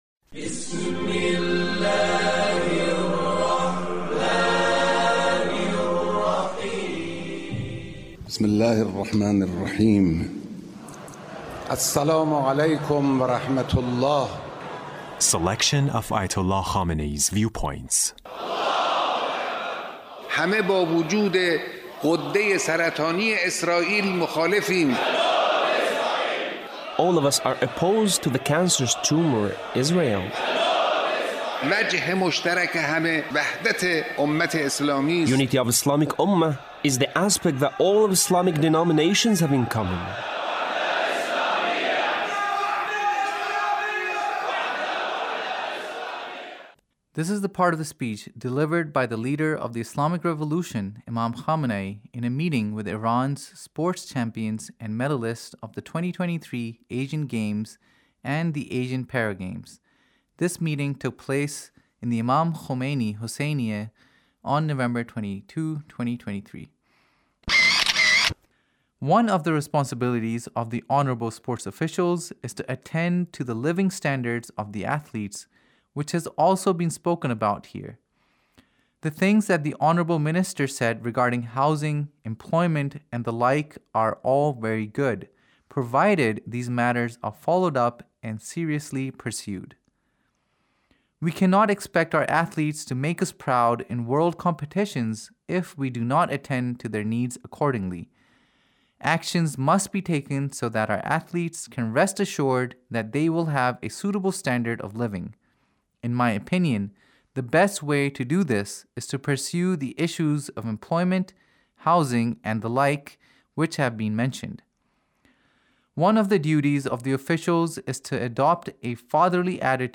Leader's Speech in a meeting with Iran’s sports champions and medalists